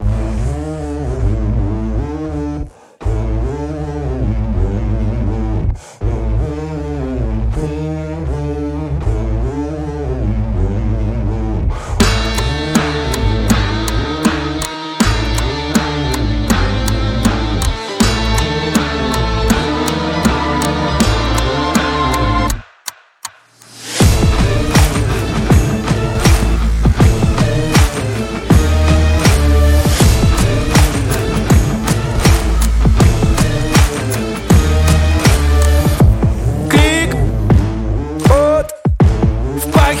поп
тиканье часов , нарастающие , драйвовые